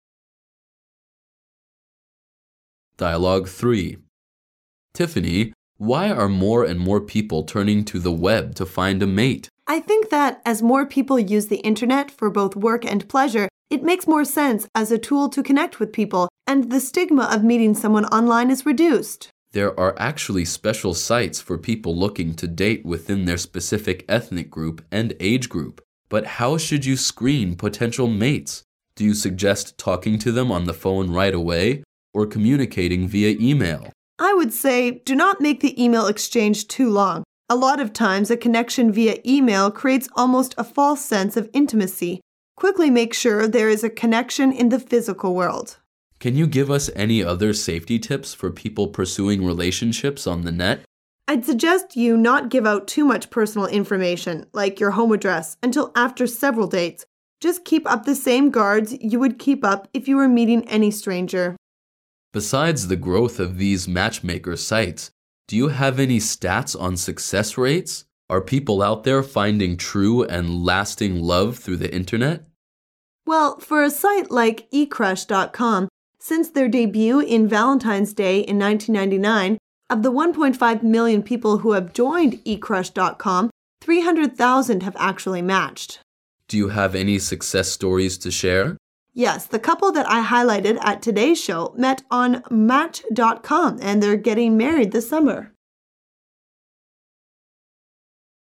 Dialoug 3